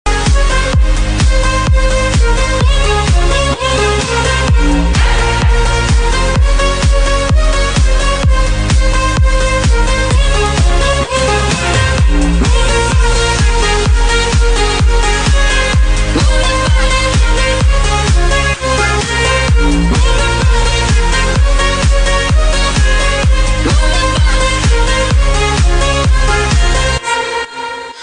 Catégorie Électronique